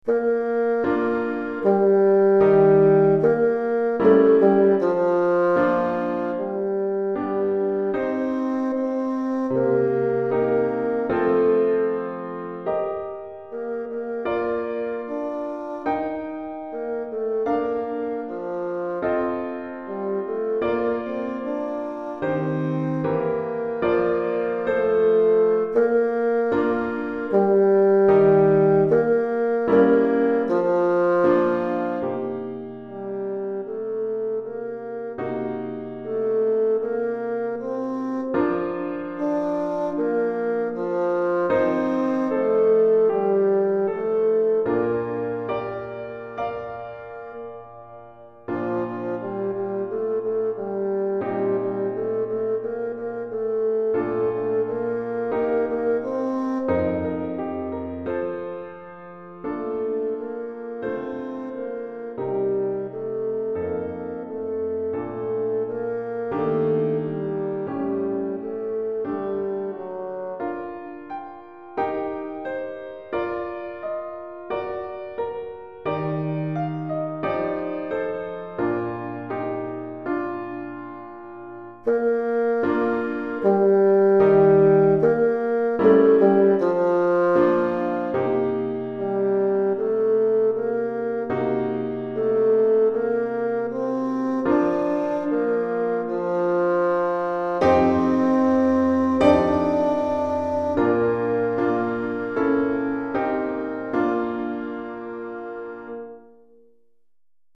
Basson et Piano